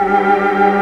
Index of /90_sSampleCDs/Giga Samples Collection/Organ/Barton Melo 16+8